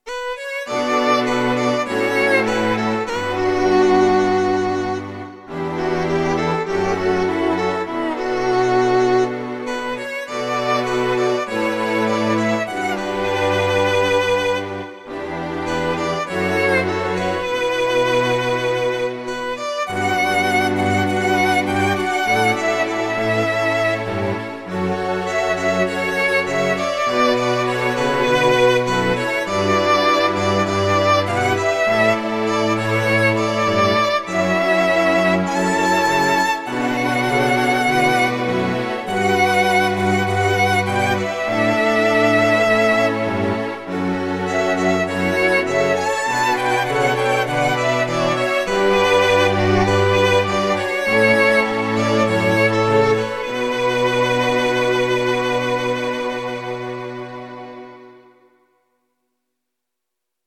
【効果音】機械のノイズ音
不快な「ビー」という機械のノイズ音です。何かしら機会が故障して変な音が鳴っている状況です。ループして使えます。...